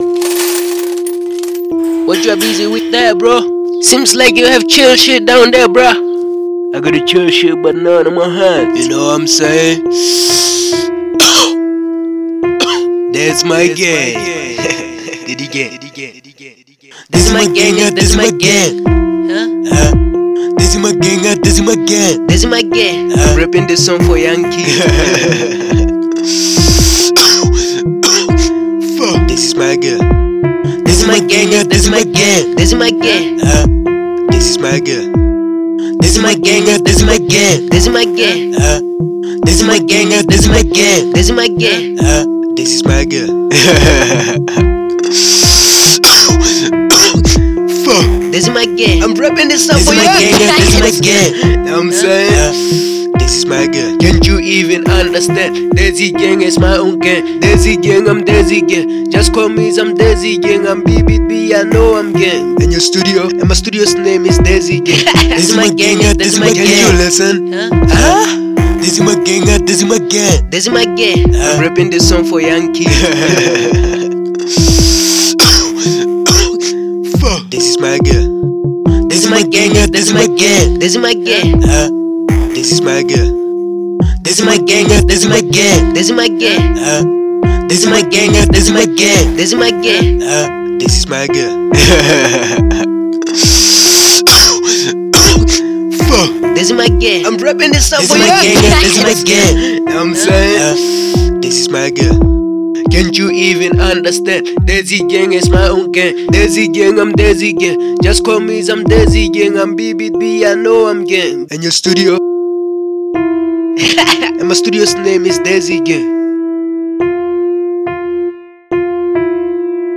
freestyle
Freestyle